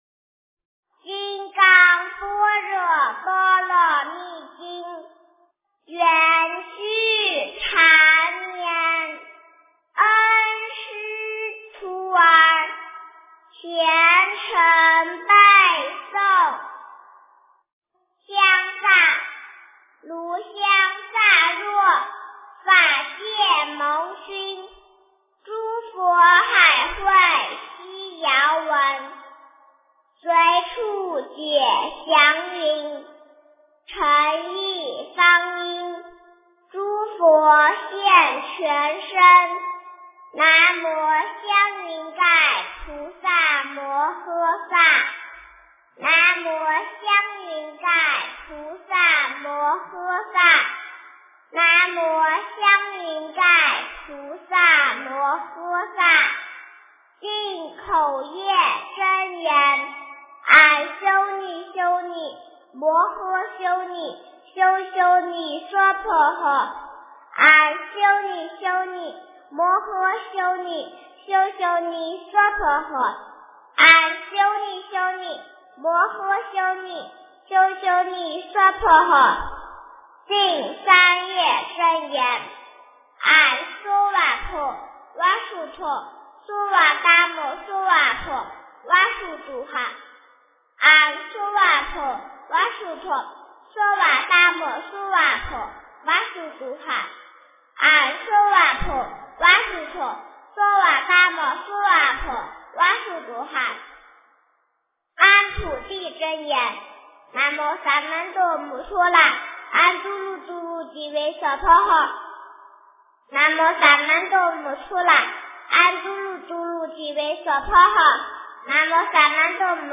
《金刚经》儿童背诵版